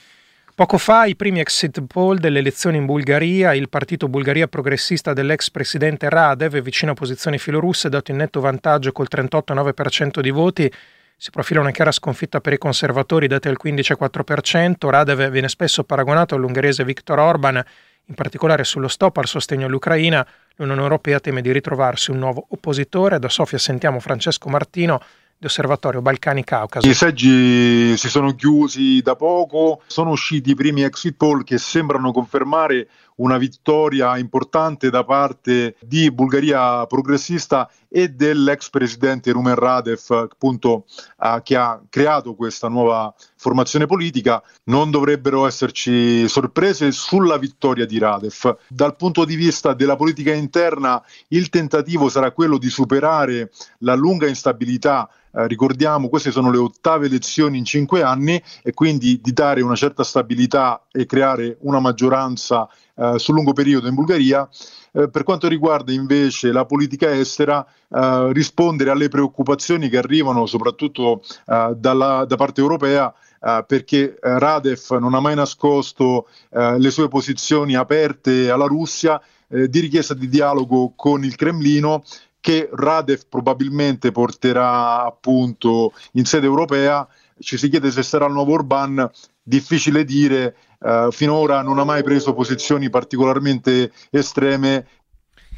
da Sofia, al Gr delle 19.00 (19 aprile 2026)